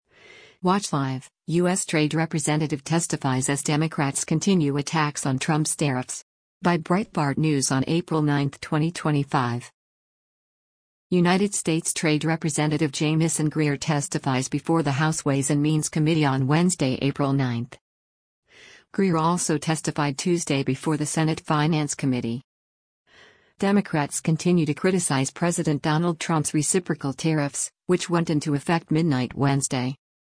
United States Trade Representative Jamieson Greer testifies before the House Ways and Means Committee on Wednesday, April 9.